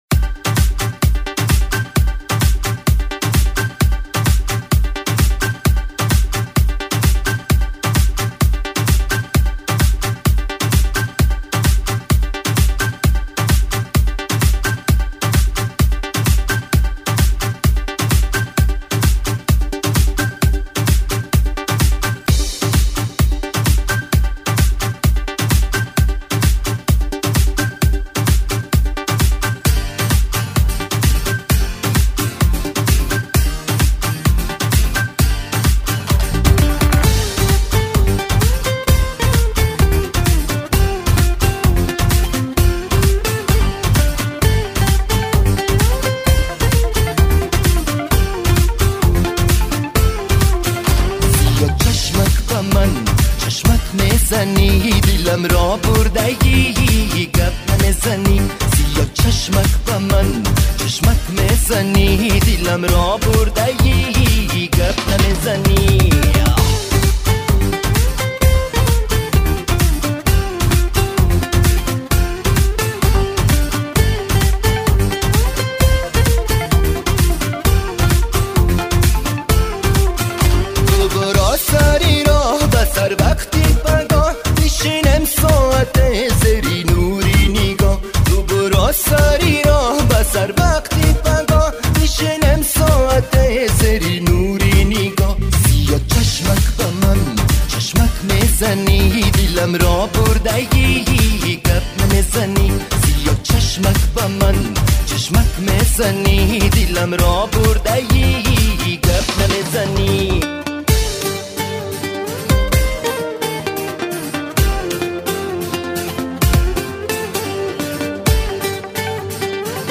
Tajikistan disco music
Эстрада